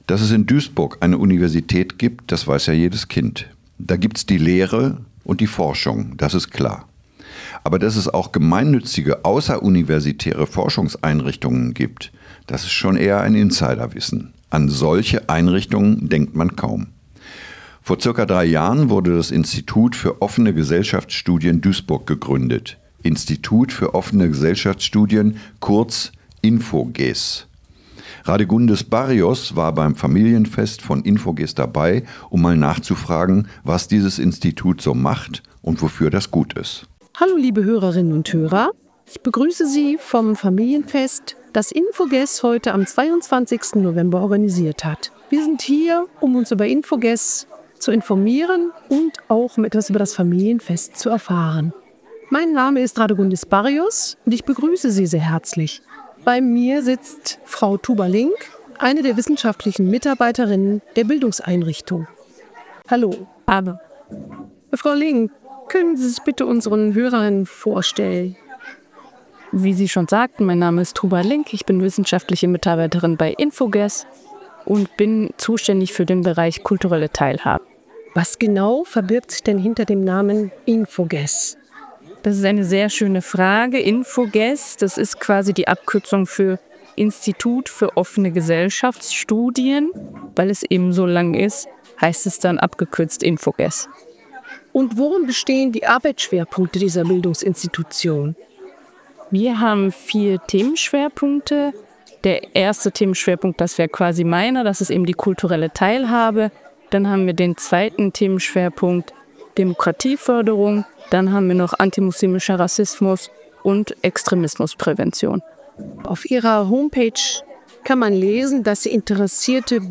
INFOGES-Interview-Website.mp3